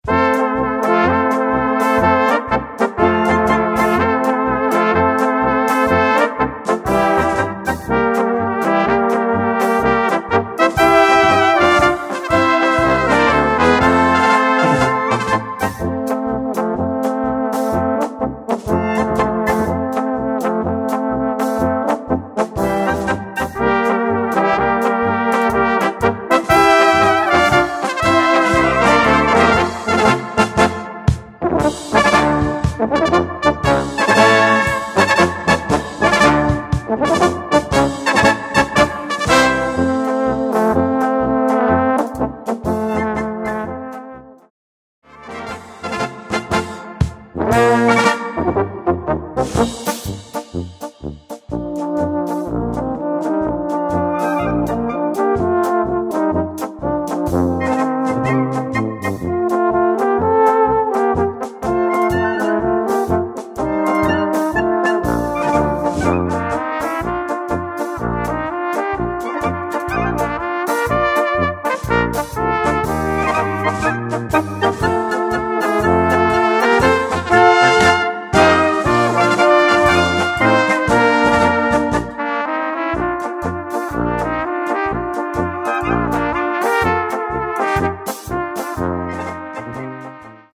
Gattung: Polka
Besetzung: Blasorchester
vermittelt spritzige Stimmung voller Elan